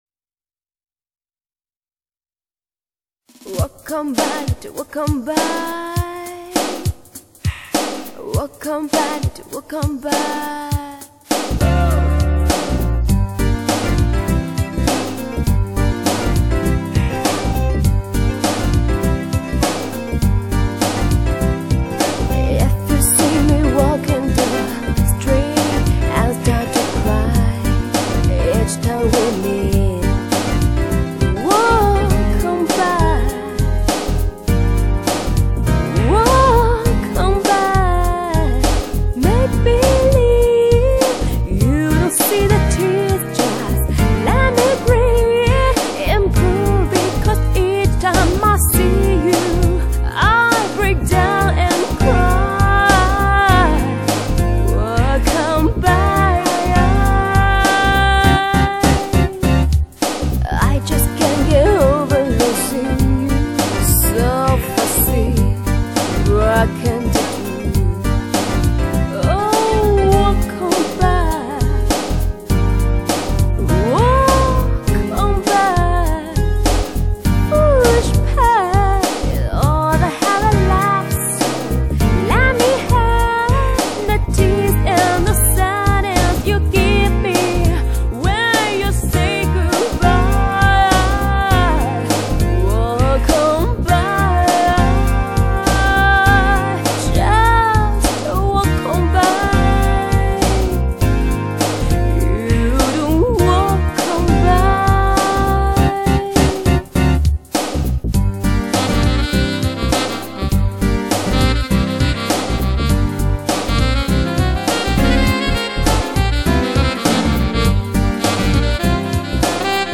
试音碟
版本: [DTS-CD]